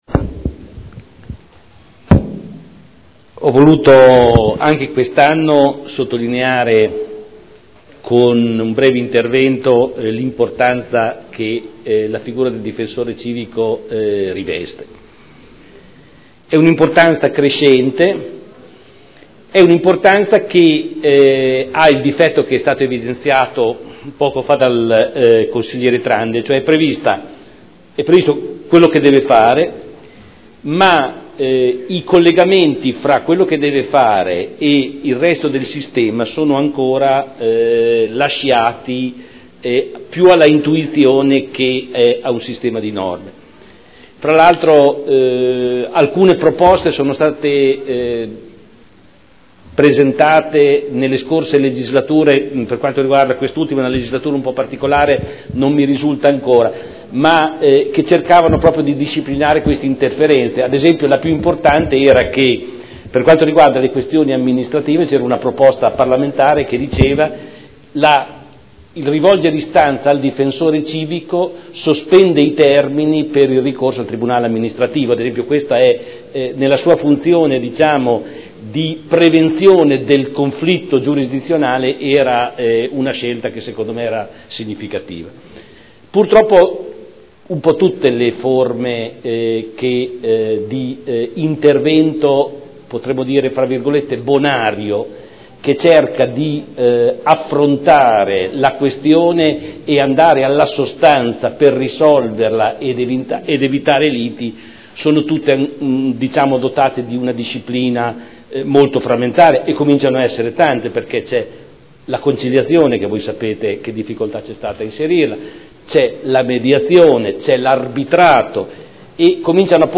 Seduta del 24 febbraio. Relazione del Difensore Civico al Consiglio Comunale sull’attività svolta nell’anno 2013. Dibattito